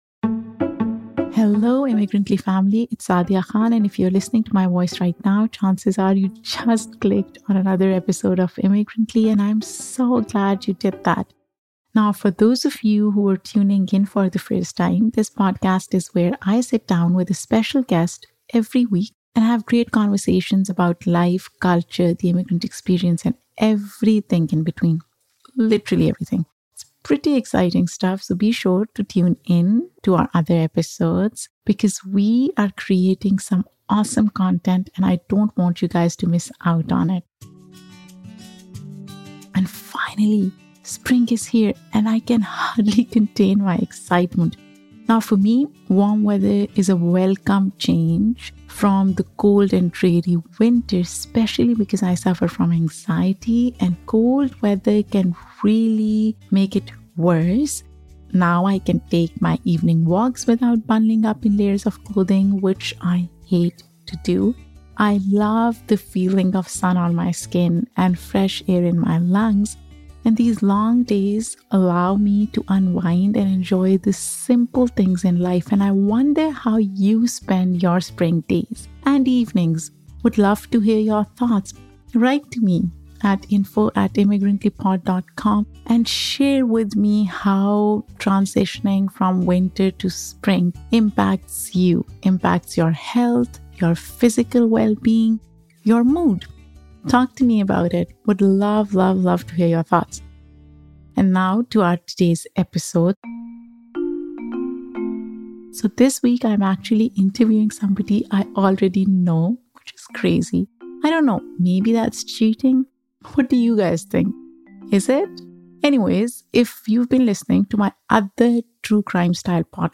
So tune in for a fun and fascinating conversation!